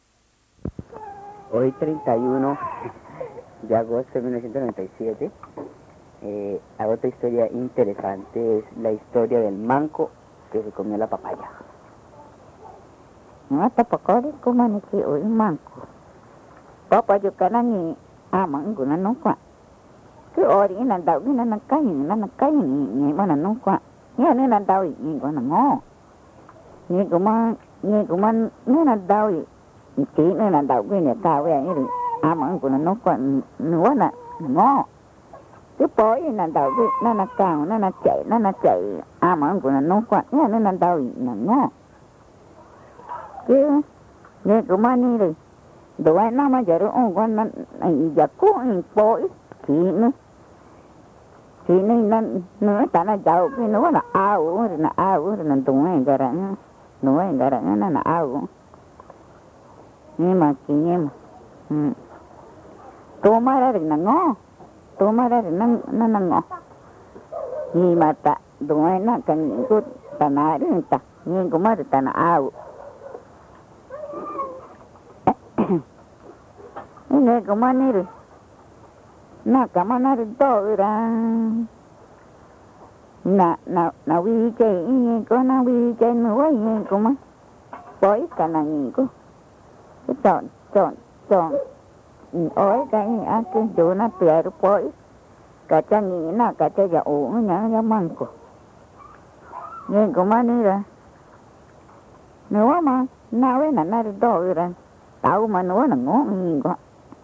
Boyahuazú, Amazonas (Colombia)